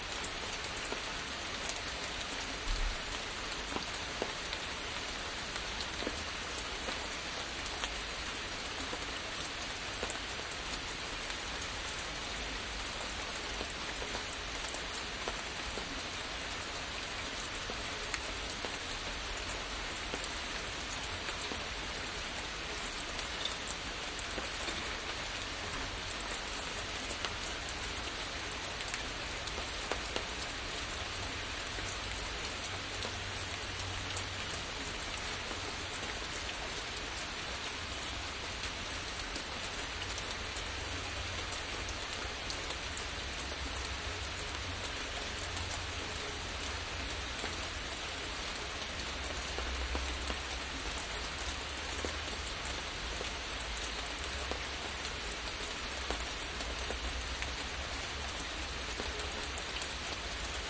雨声 " 雨滴在窗台上 7 (关闭)
描述：用Zoom H1记录。
Tag: 雨滴 氛围 湿 天气 窗口 性质 窗台 下雨